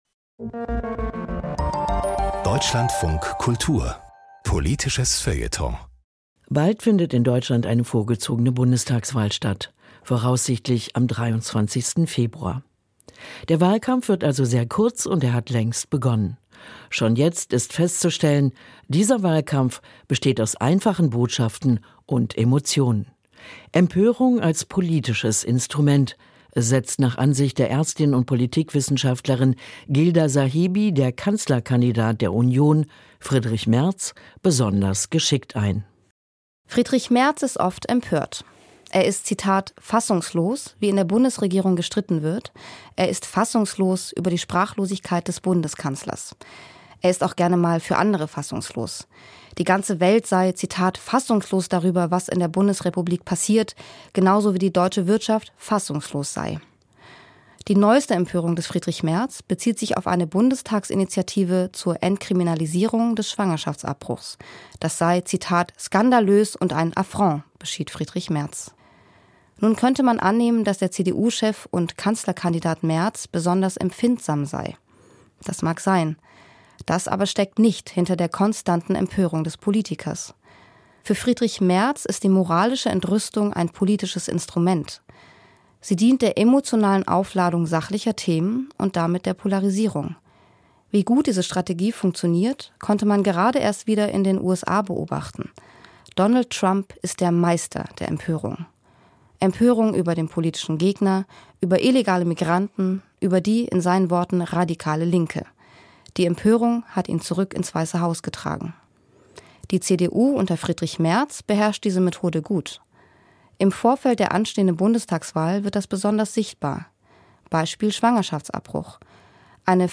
Kommentar: Seine Empörung ist eine perfide Taktik